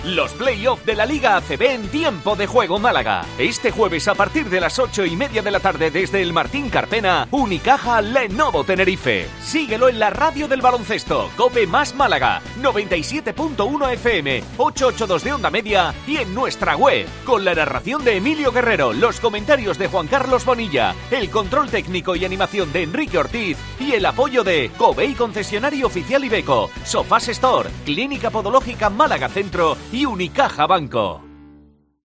Promo basket